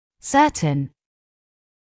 [‘sɜːtn][‘сёːтн]уверенный; некий, некоторый; точный, определенный